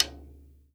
CLICKONR.1-R.wav